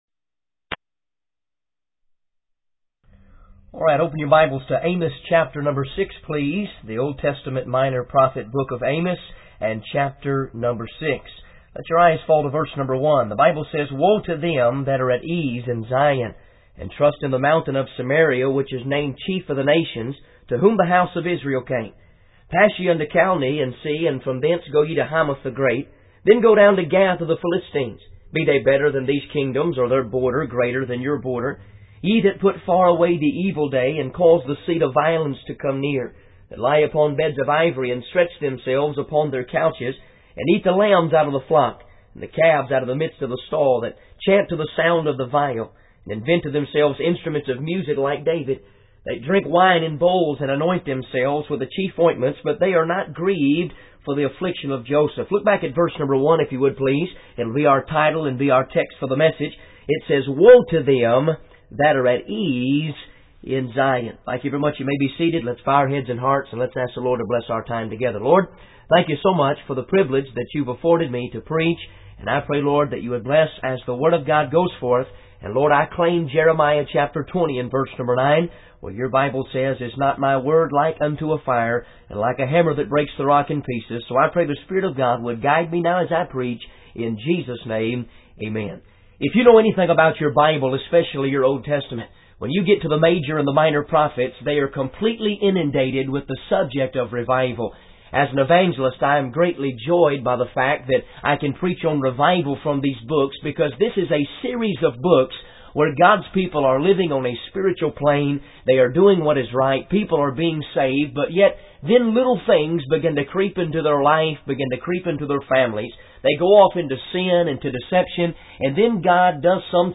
In this sermon, the preacher starts by asking a rhetorical question: 'Can two walk together except they be agreed?' He emphasizes that people who are walking in the same direction cannot be divided on certain issues because they will not get along.